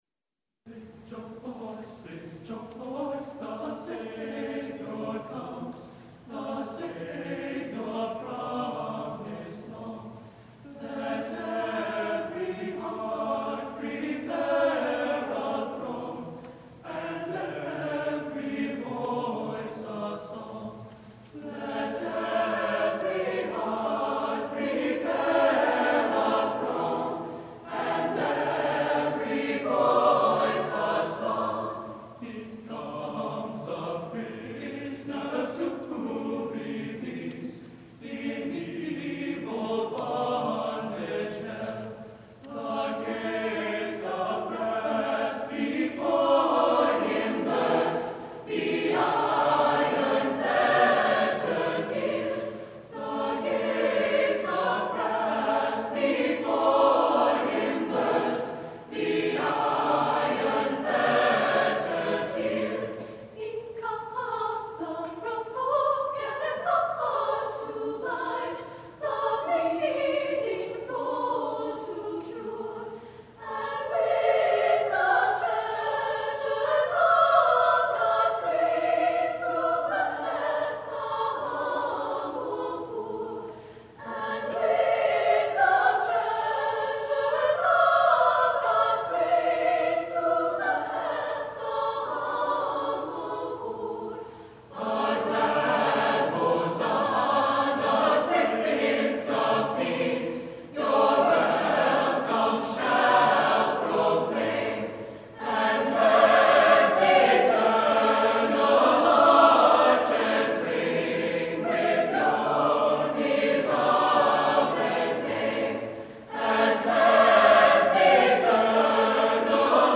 Voicing: SSATTB a cappella